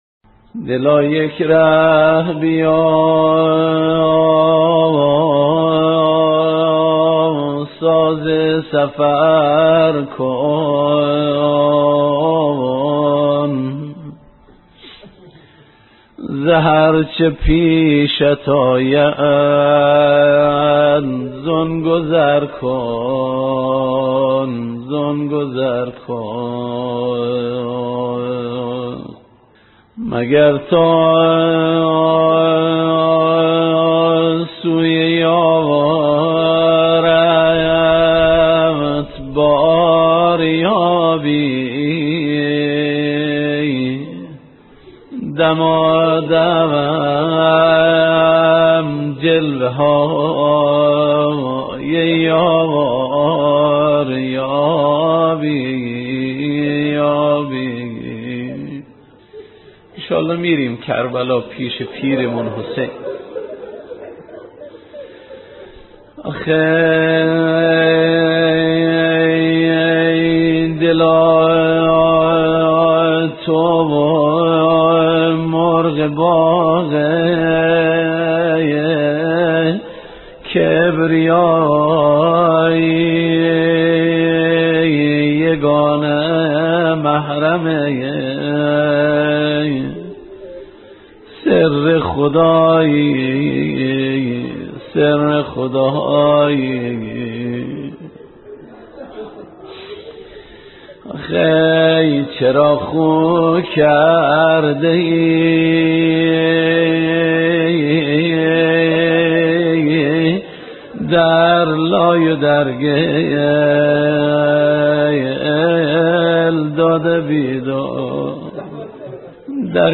روضه ی دلتنگی